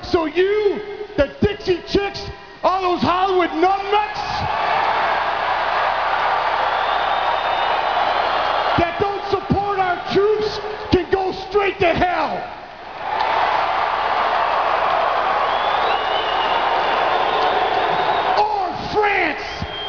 Enunciation isn’t Scotty’s strong point.